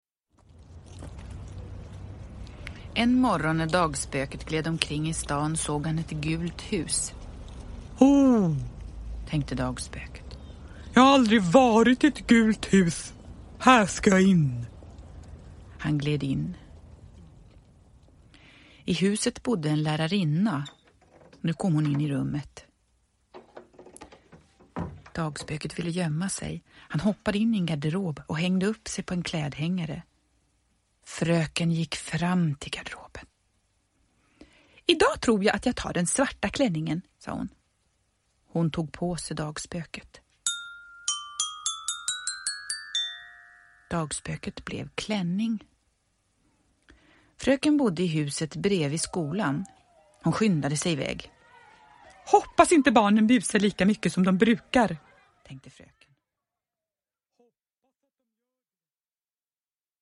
Dagspöket i skolan – Ljudbok – Laddas ner
Uppläsare: Jujja Wieslander